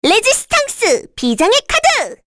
Rehartna-Vox_Skill6_kr_b.wav